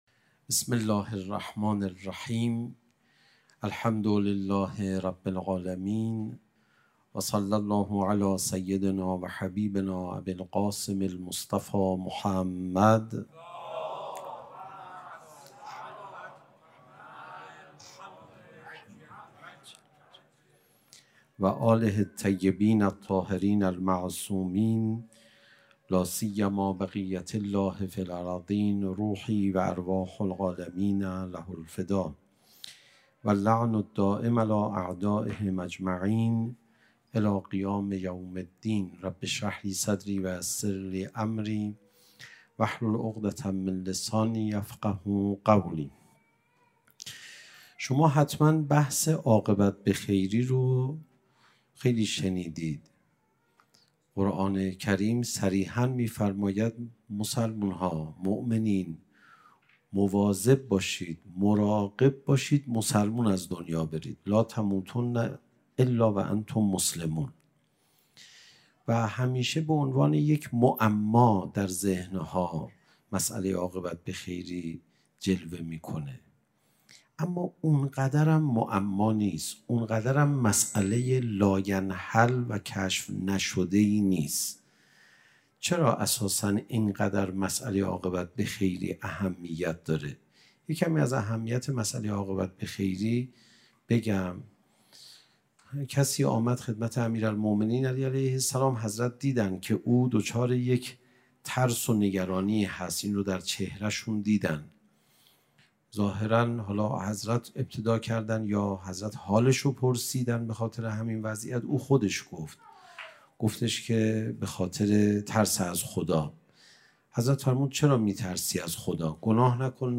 سخنرانی: انحطاط متحجرانه، انعطاف‌پذیری متفکرانه